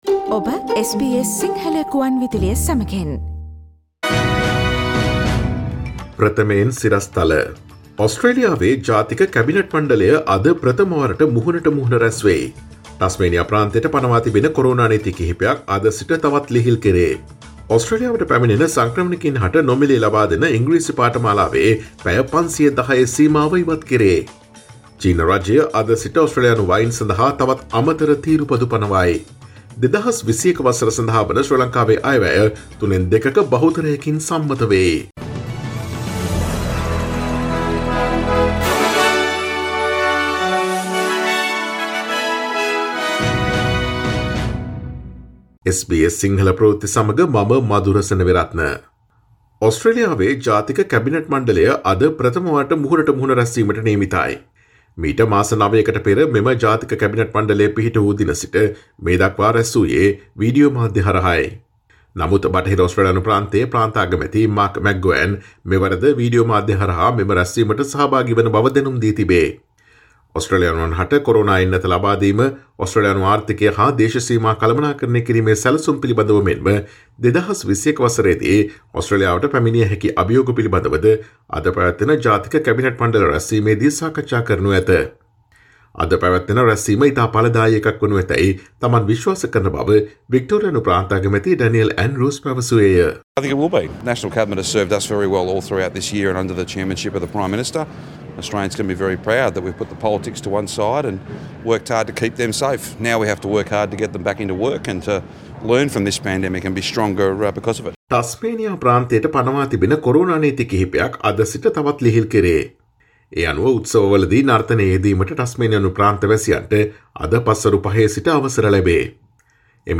Today’s news bulletin of SBS Sinhala radio – Friday 11 December 2020